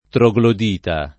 troglodita [ tro g lod & ta ] s. m.; pl. ‑ti